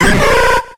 Grito de Larvitar.ogg
Grito_de_Larvitar.ogg